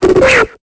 Cri de Kraknoix dans Pokémon Épée et Bouclier.